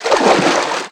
STEPS Water, Stride 04.wav